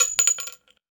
weapon_ammo_drop_10.wav